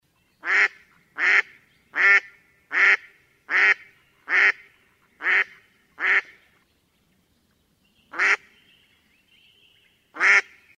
утка крякает